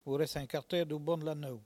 Patois - archive